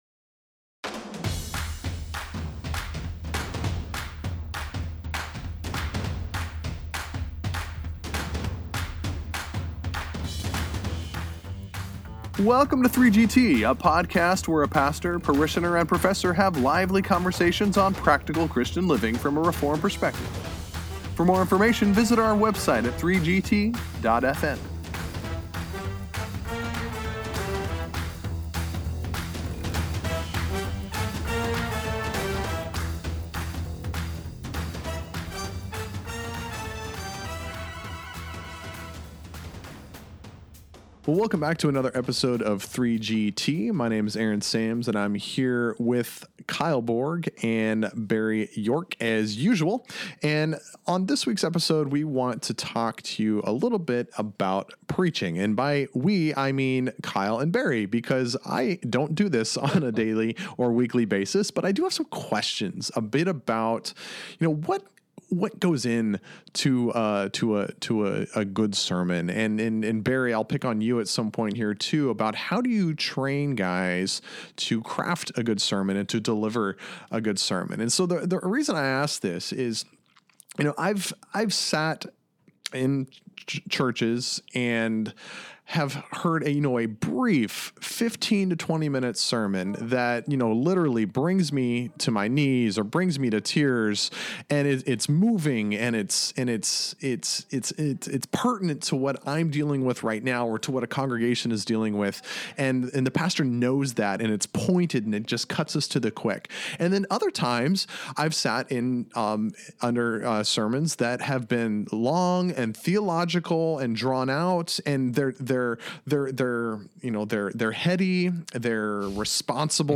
Episode 55: Real Preachin’ – Three Guys Theologizing
You will want to listen and enjoy this vigorous discussion that speaks to those on both sides of the pulpit!